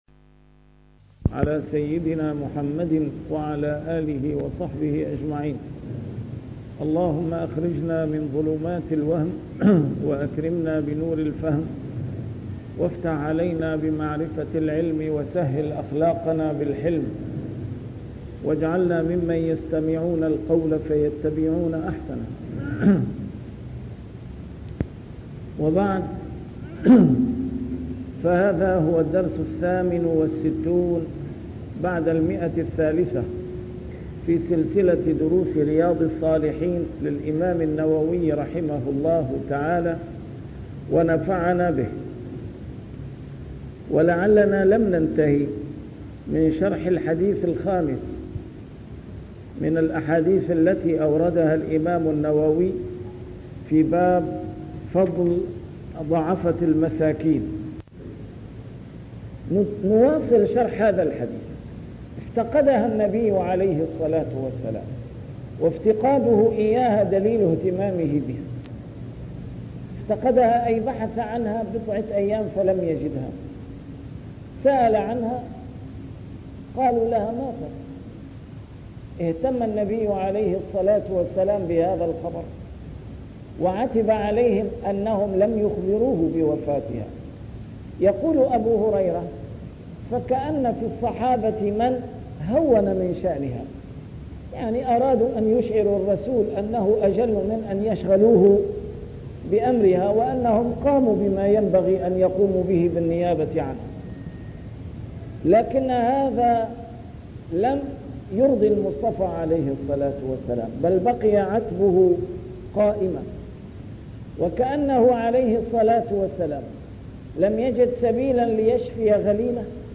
A MARTYR SCHOLAR: IMAM MUHAMMAD SAEED RAMADAN AL-BOUTI - الدروس العلمية - شرح كتاب رياض الصالحين - 368- شرح رياض الصالحين: فضل ضعفة المسلمين